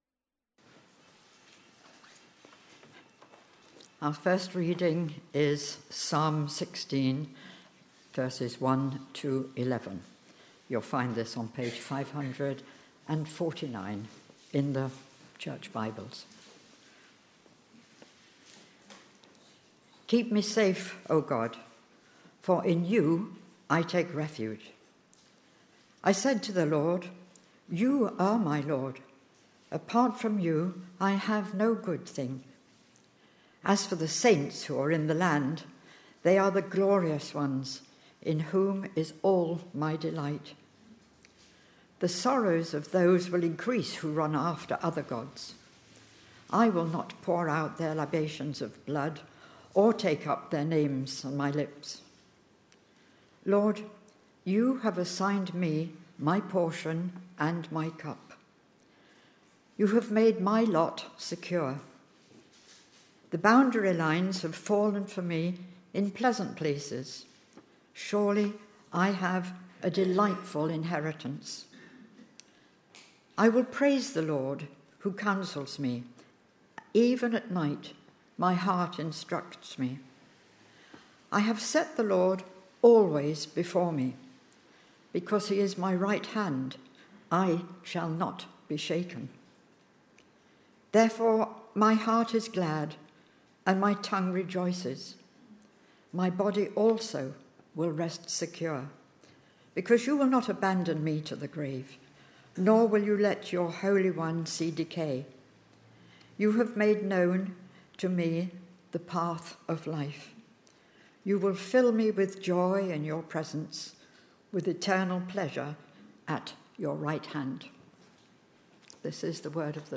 With Bible readings from Psalm 16:1-11 and Philippians 4:2-9 .
Readings-and-Sermon-Sunday-16th-November.mp3